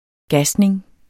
Udtale [ ˈgasneŋ ]